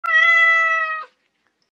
Meow Sound Effect U Lb1 Ze Rgl K (audio/mpeg)
CAT